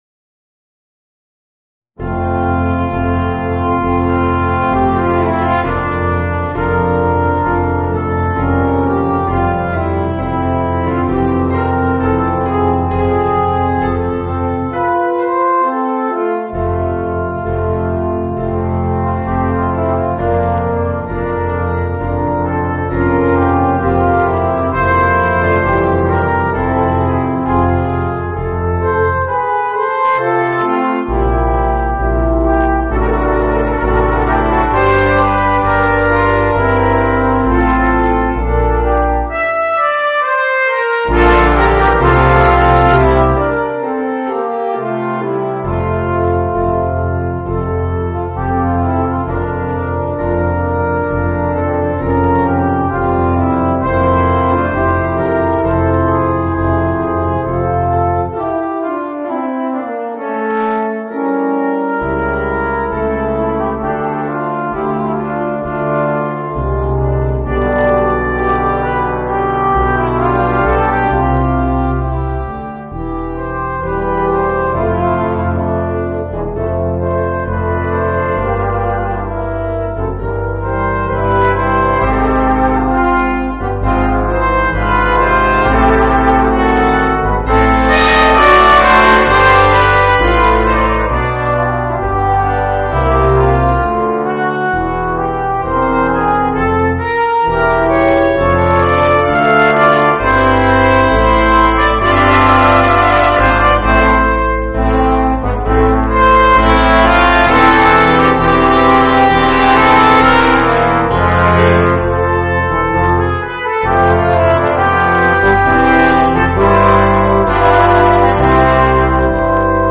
Voicing: 5 - Part Ensemble and Piano / Keyboard